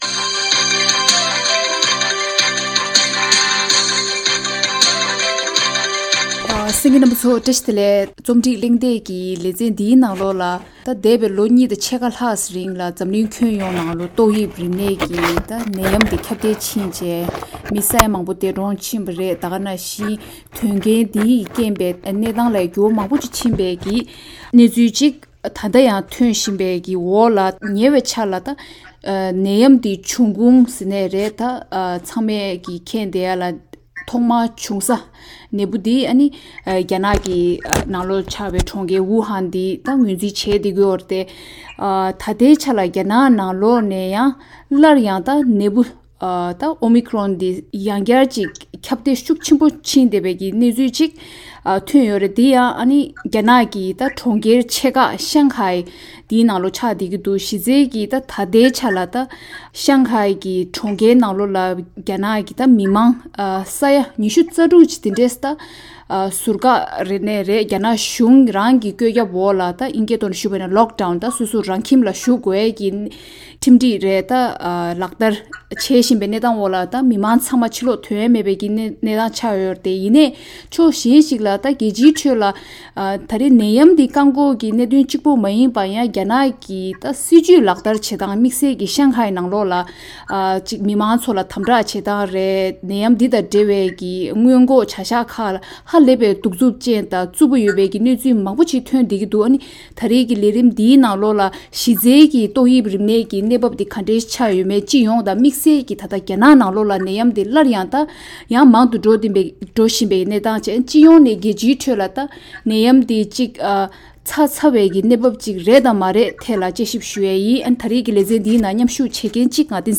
གླེང་མོལ་གནང་བའི་ལས་རིམ།